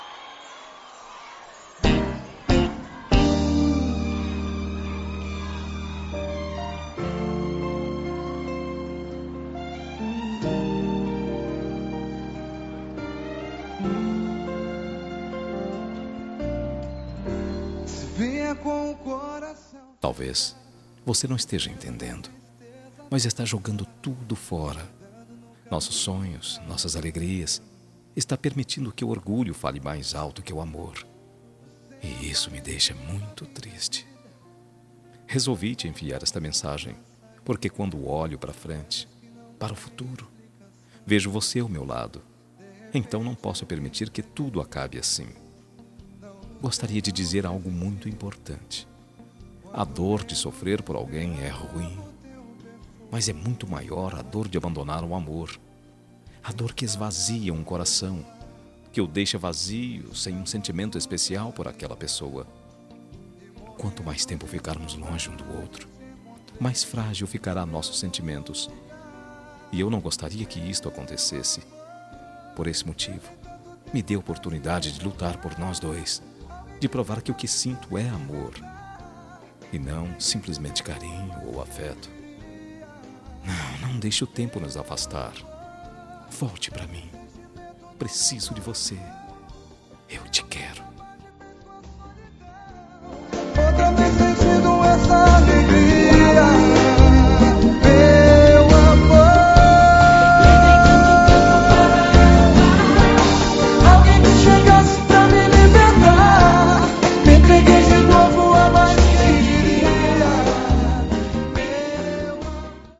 Telemensagem de Reconciliação – Voz Masculina – Cód: 7545